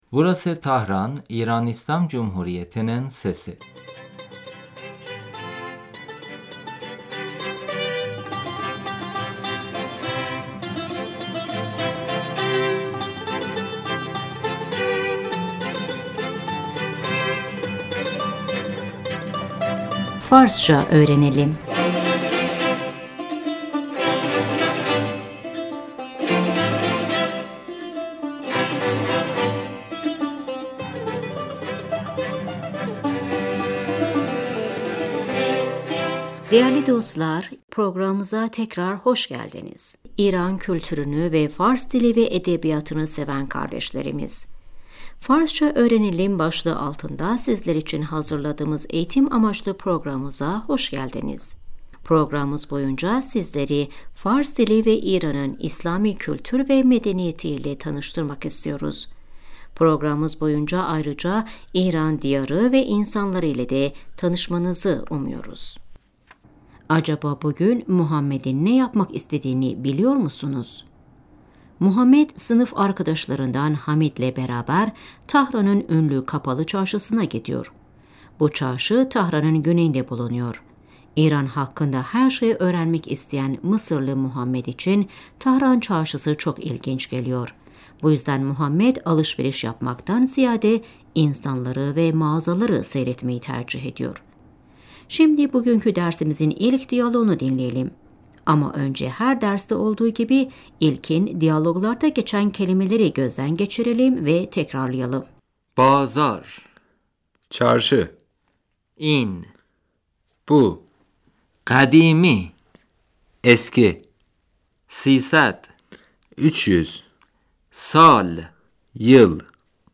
صدای همهمه مردم - عبور ماشینها Kalabalığın gürültüsü, trafik sesi محمد - حمید ، فکر می کنم این بازار خیلی قدیمی است .